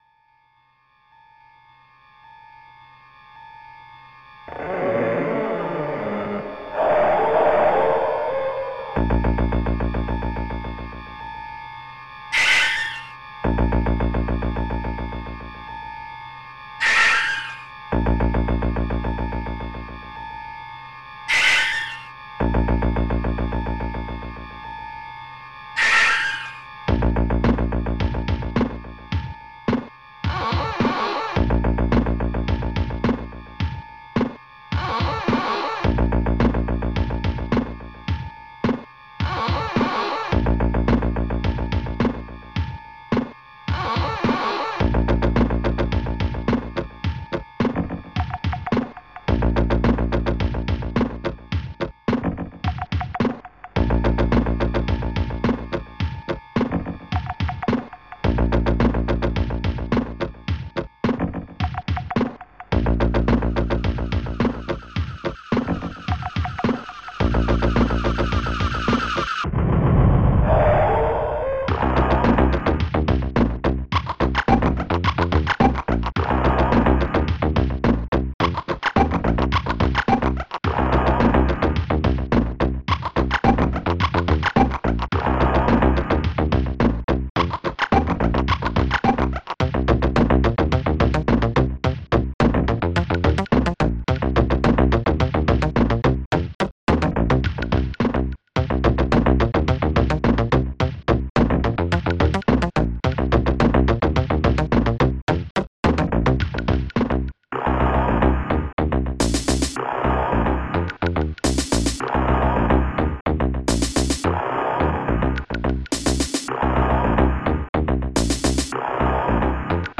Type Amos Music Bank Tracker
Instruments dragonsynth2 strings2 bassdrum8 snaredrum3 hihat1 hihat7 strings3 blast1 effect1 effect8 speech5 snaredrum2 mixsynth3 dragonsynth1 effect14